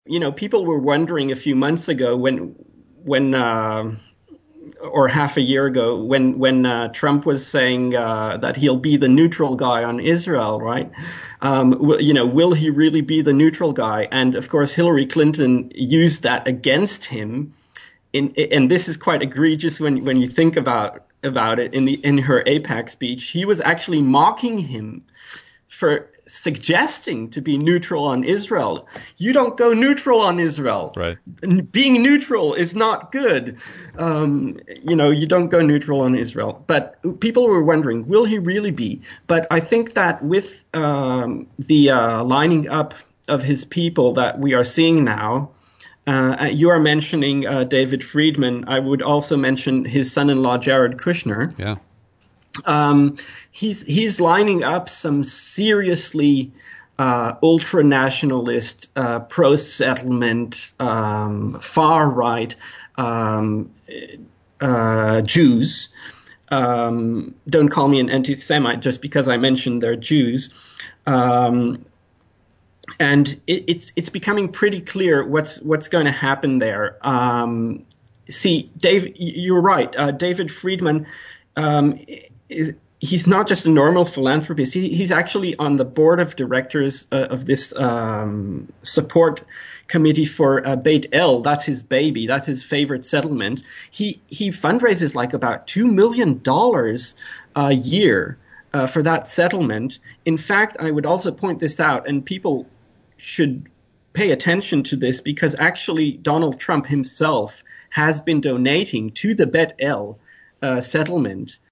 There’s much more in this wide-ranging conversation.